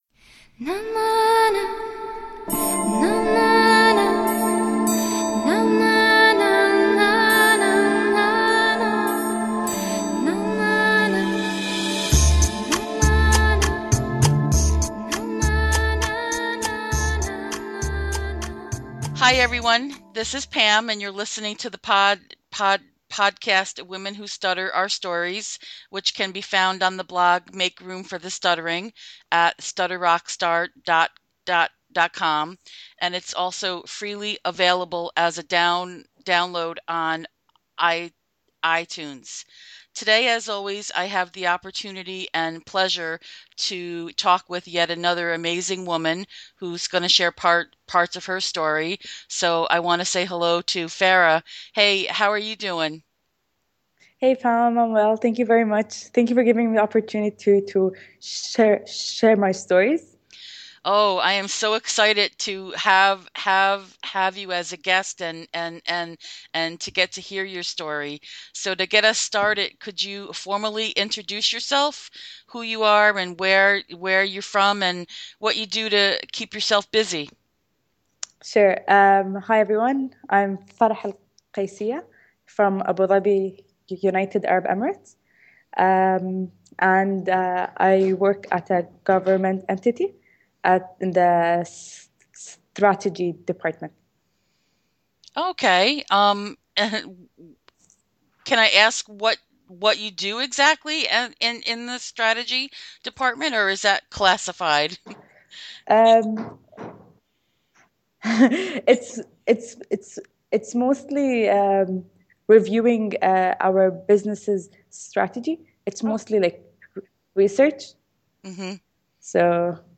This was a great conversation, where we hear a voice from another part of the world.